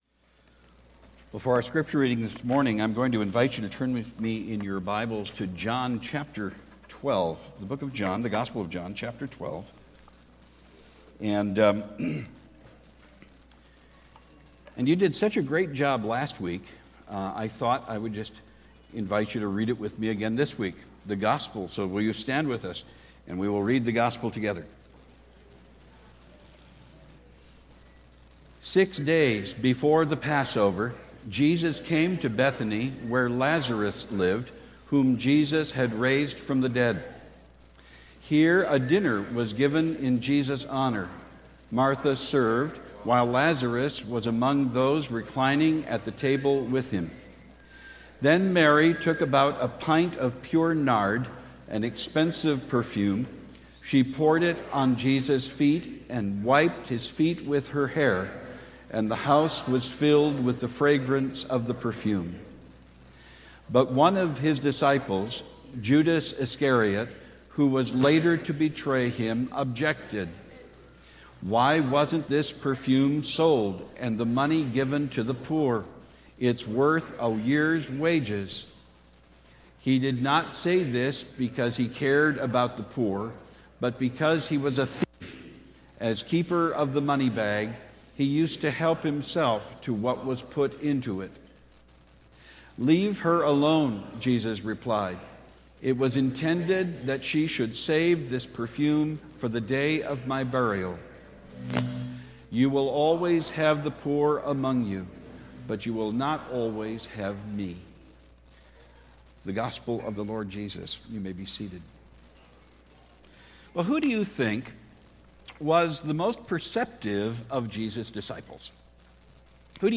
Current Sermon Living at His Feet Are You a Disciple? John 12:1-8 Guest Speaker February 16, 2020 Disciples of Love Are You a Disciple? 1 John 4:7-12 Guest Speaker February 9, 2020 Disciples Aligned by Prayer Are You a Disciple?